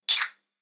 Golpe de crótalos al cerrarlos
crótalo
instrumento de percusión